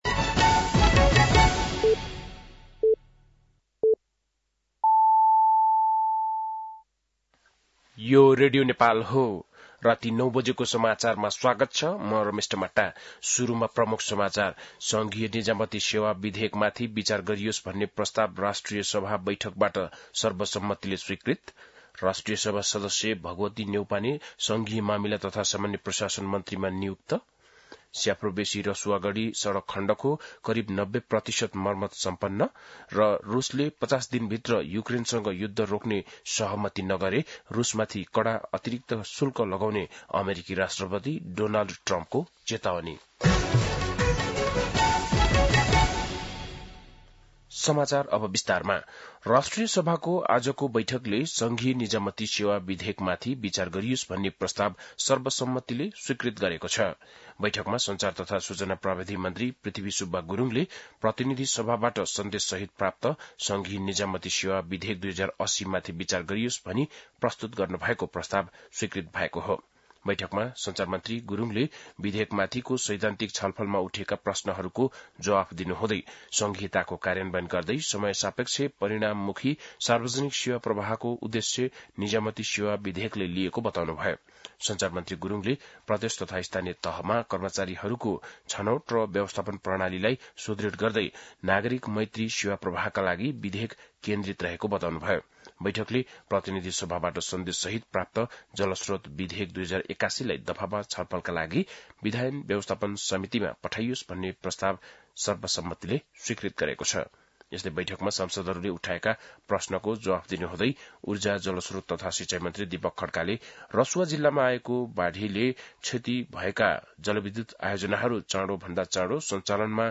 बेलुकी ९ बजेको नेपाली समाचार : ३१ असार , २०८२
9-pm-nepali-news-3-31.mp3